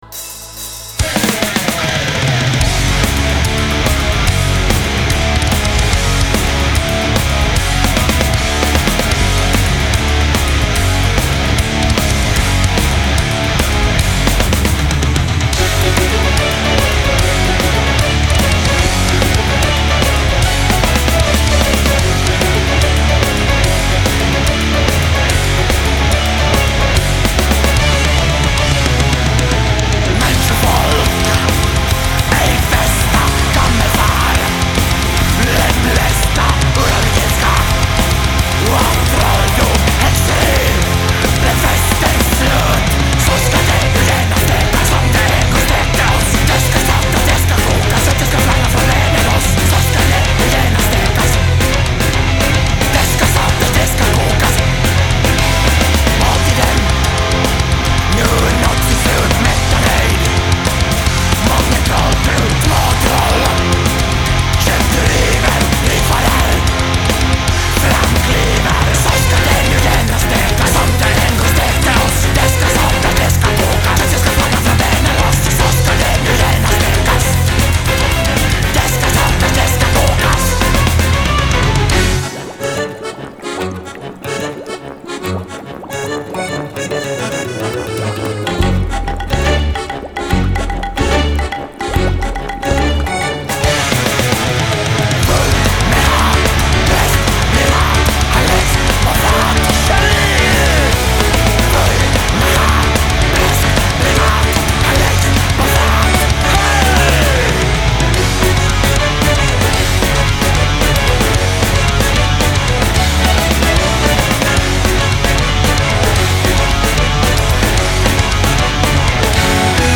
Metal